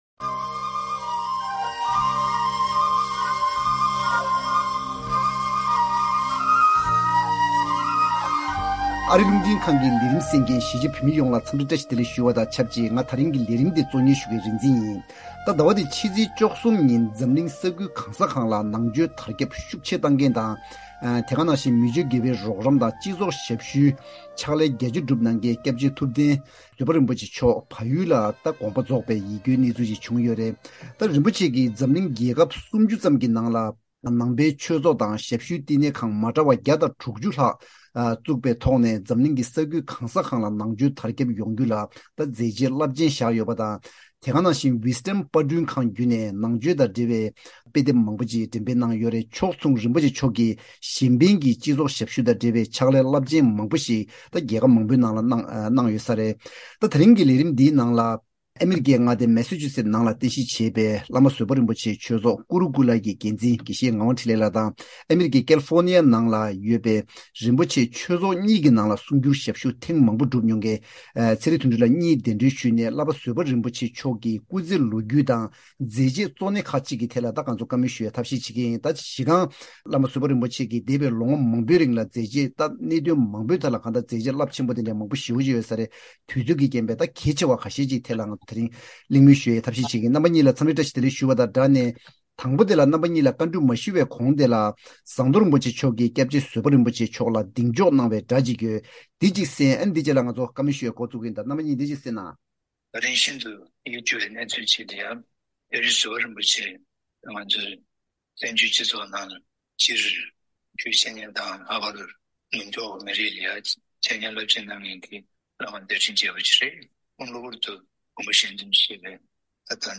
Table Talk-The Life and the Work of Lama Zopa Rinpoche
༄༅། །ཉེ་དུས་བལ་ཡུལ་དུ་སྐྱབས་རྗེ་ཐུབ་བསྟན་བཟོད་པ་རིན་པོ་ཆེ་མཆོག་དགོངས་པ་ཆོས་དབྱིས་སུ་འཐིམས་ཏེ་ད་ཆ་ཐུགས་དམ་ལ་བཞུགས་ཡོད་པ་དང་ ཁོང་གི་སྐུ་ཚེའི་ལོ་རྒྱུས་དང་། རིན་པོ་ཆེ་མཆོག་གི་མཛད་རྗེས་ཁག་གཅིག་ཐད་ལ་འབྲེལ་ཡོད་གཉིས་གདན་ཞུ་བྱས་ཏེ་གླེང་མོལ་ཞུ་ཡི་ཡིན།